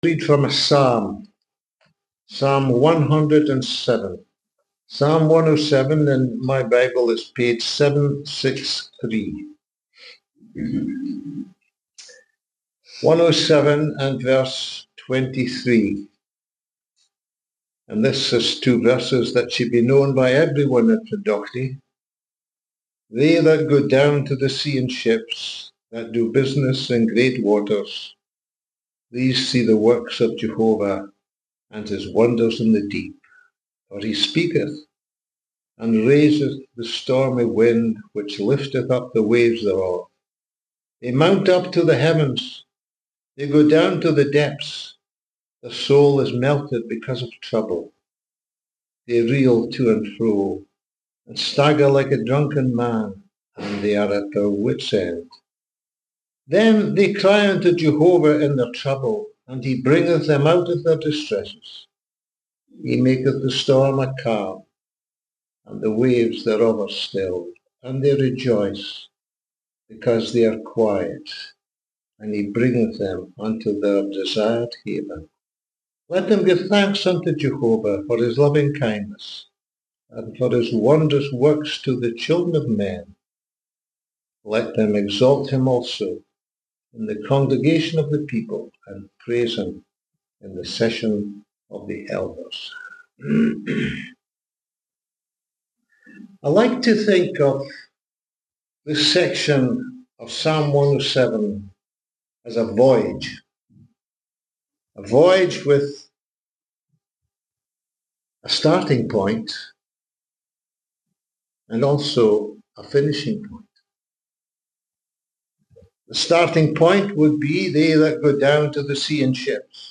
Gospel Preachings